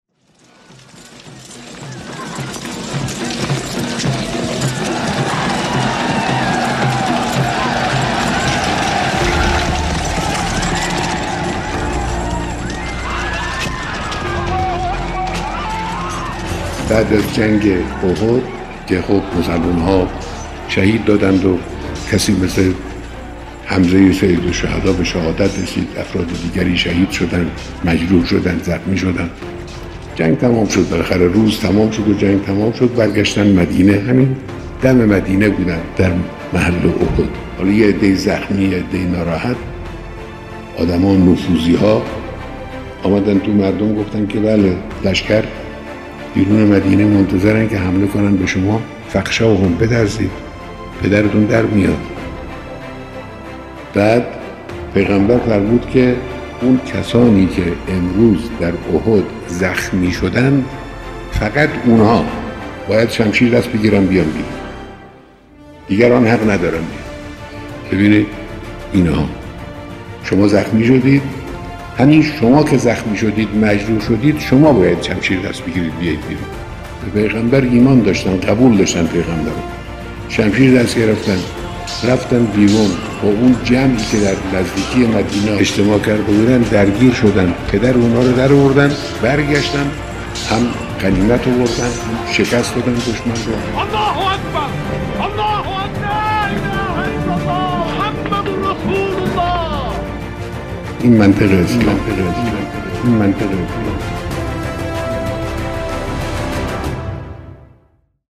صداهنگ